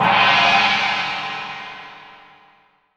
Index of /90_sSampleCDs/AKAI S6000 CD-ROM - Volume 3/Crash_Cymbal2/MALLET_CYMBAL